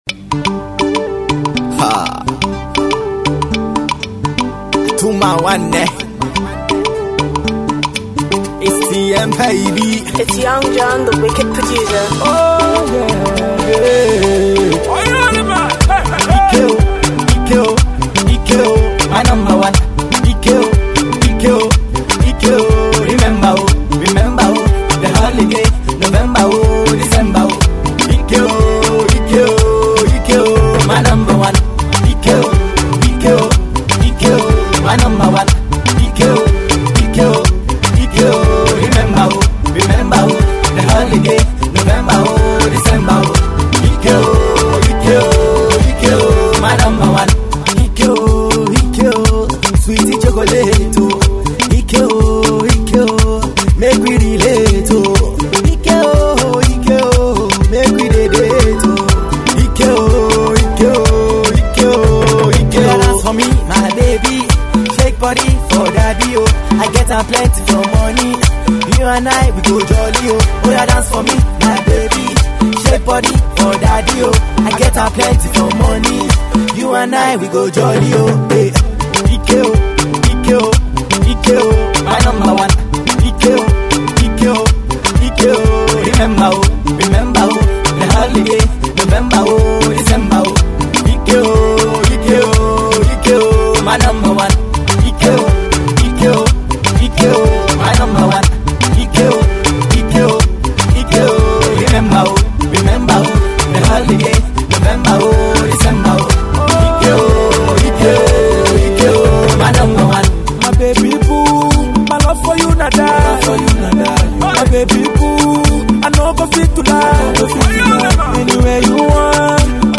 Hot naija music
club banger and hot party song